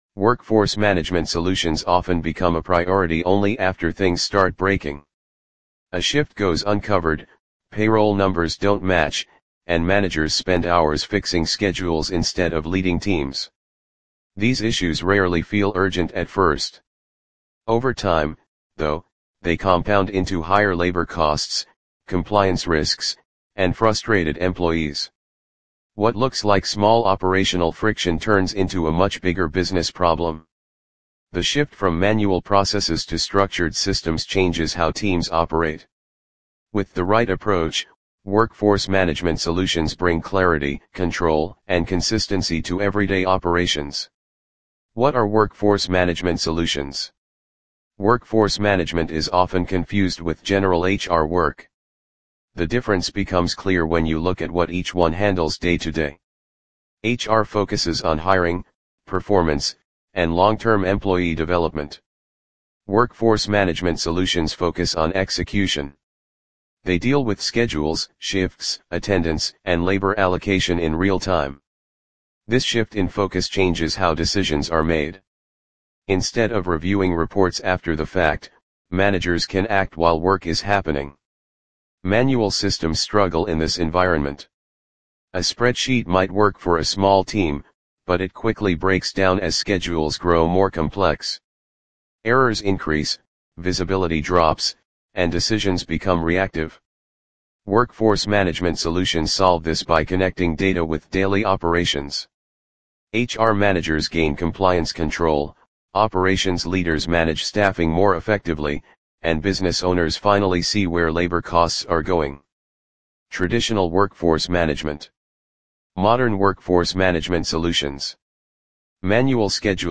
Read Aloud!